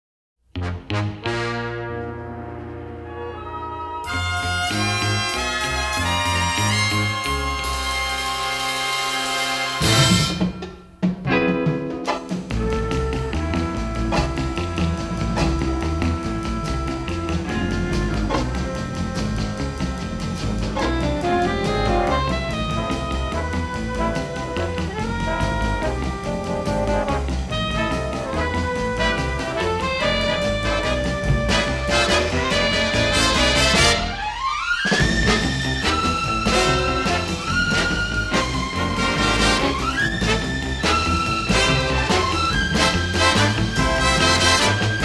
are presented in dynamic stereo